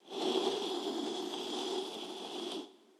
SFX_Door_Slide_01.wav